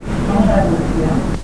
Escuche espectrales voces del mas alla. Estas son la llamadas Psicofonias. Voces de fanatsmas capturadas en radios , televisores sin señal y corrientes de agua.
Hombre platicando sobre un judio y un cristiano.